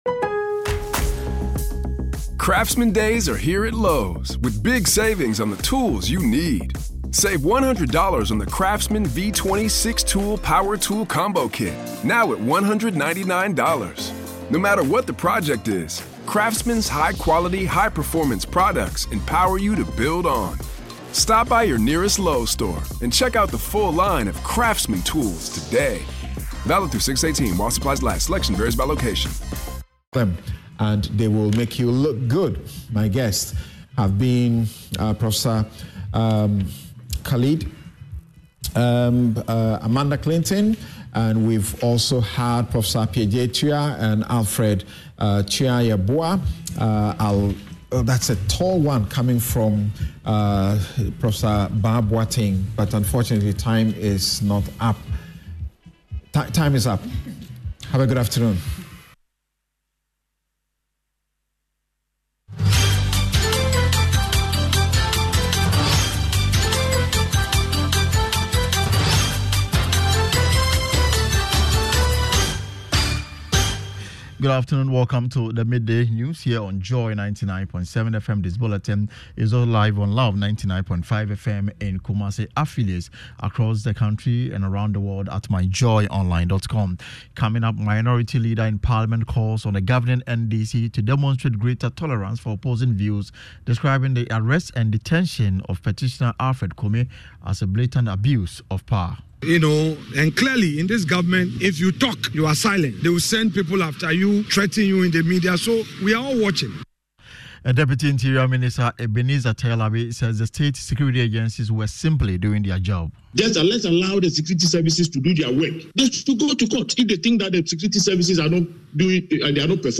Joy FM Afternoon News Bulletin